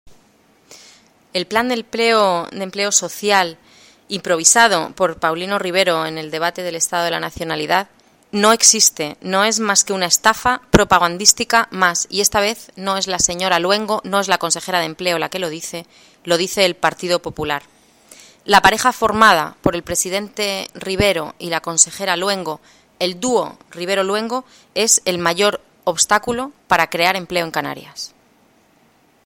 La portavoz de empleo del PP en el Parlamento de Canarias y el portavoz económico, Jorge Rodríguez, comparecieron este mediodía en rueda de prensa para advertir del “engaño” que supone el anuncio presidencial, “que solo perseguía robar un titular a la realidad y que ha sido desmentido por la propia consejera”.